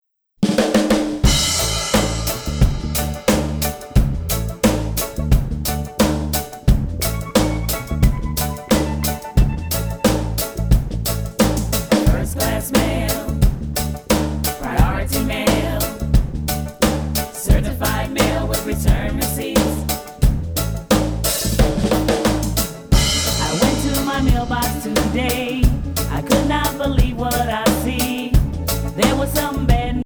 female vocal sensation